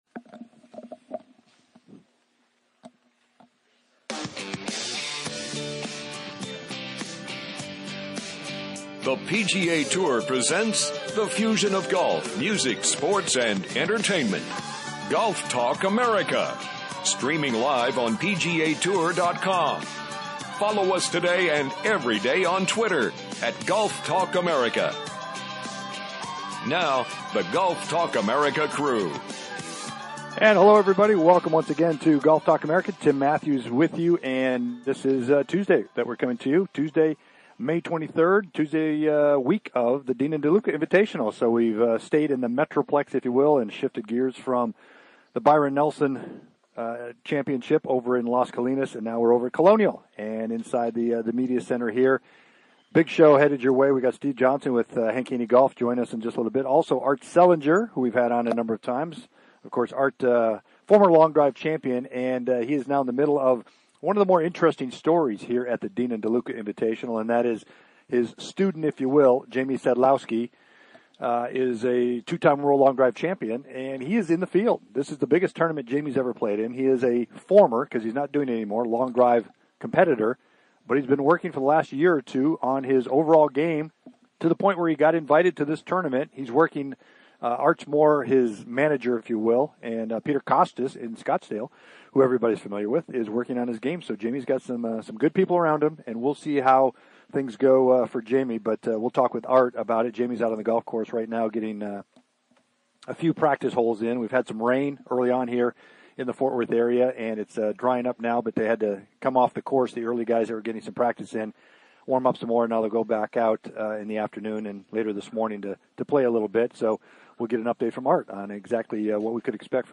"LIVE" FROM THE DEAN & DELUCA INVITATIONAL AT COLONIAL COUNTRY CLUB IN FORT WORTH, TEXAS